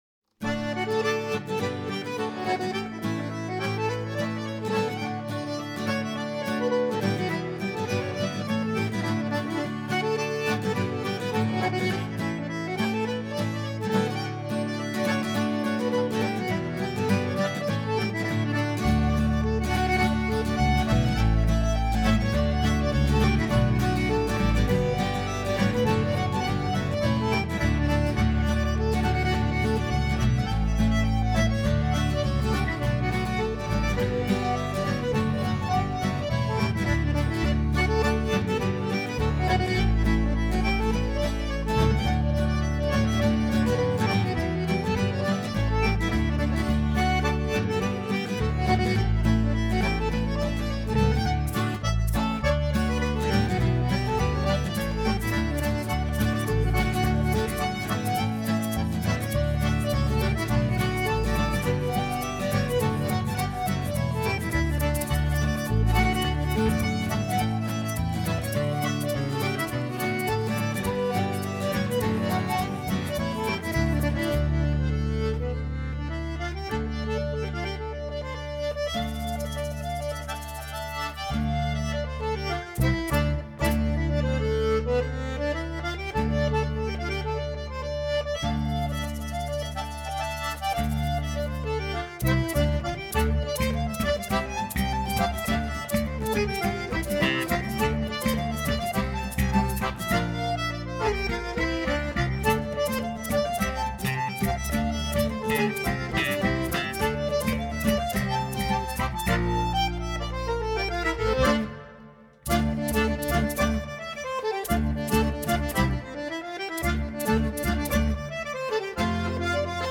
aufgenommen 1996 im Tonstudio